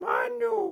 Audio / SFX / Characters / Voices / PigChef